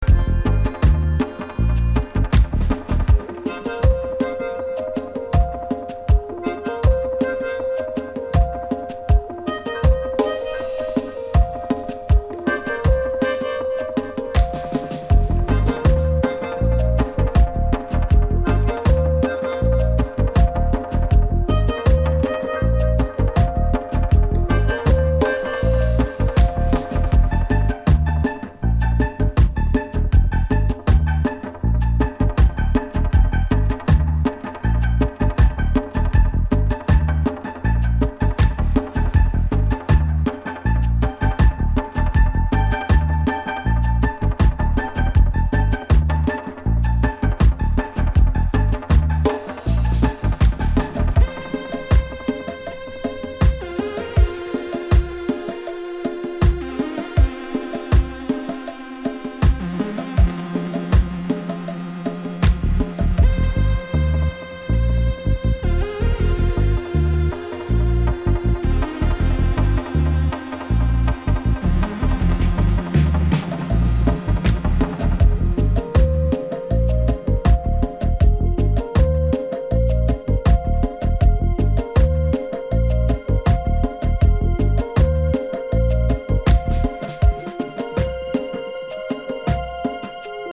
Electronix House